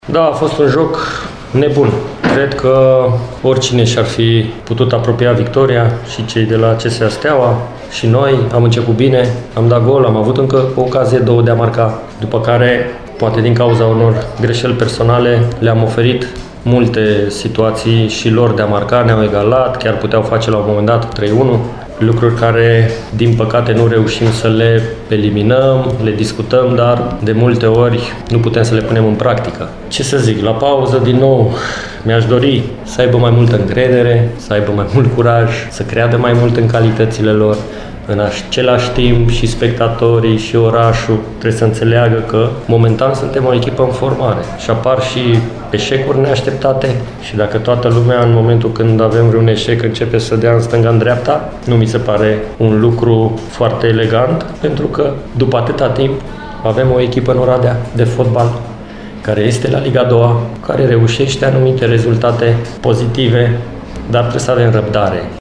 La final, antrenorul orădenilor, Erik Lincar, a făcut un apel către public să aibă mai multă răbdare cu echipa: